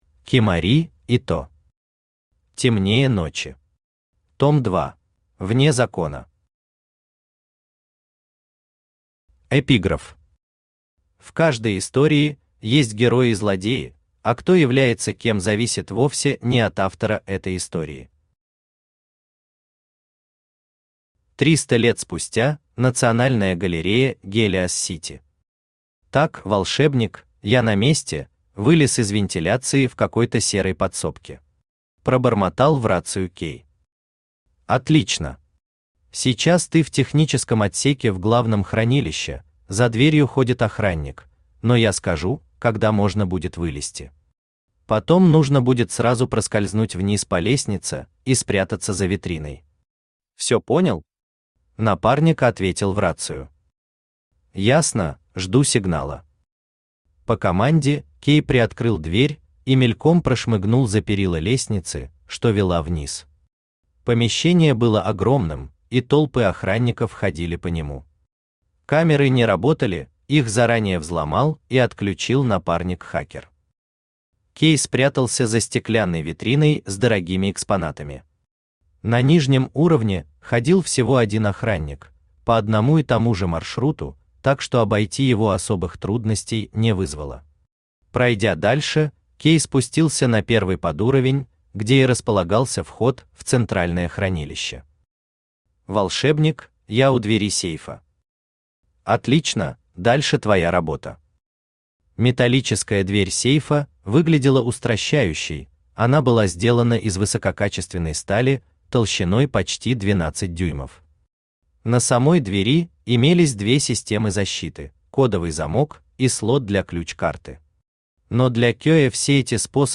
Аудиокнига Темнее ночи. Том 2 | Библиотека аудиокниг
Том 2 Автор Кимори Ито Читает аудиокнигу Авточтец ЛитРес.